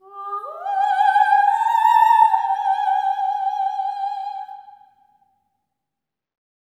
OPERATIC07-L.wav